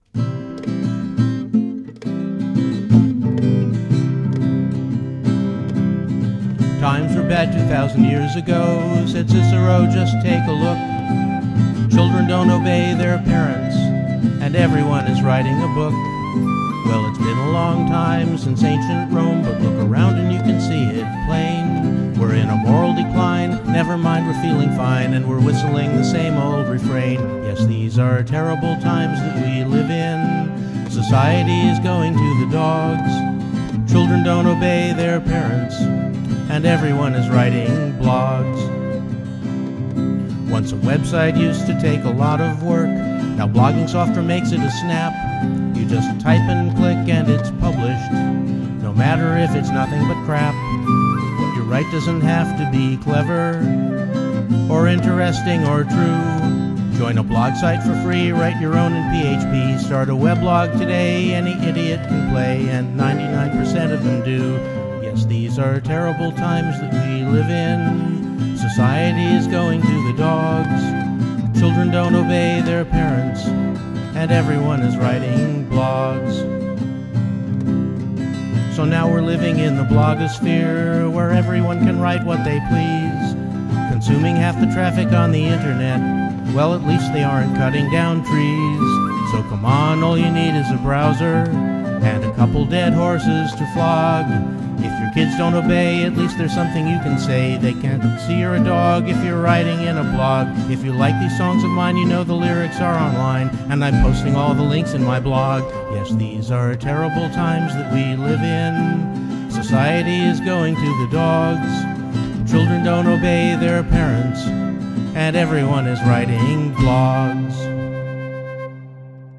They're not necessarily in any shape to be heard!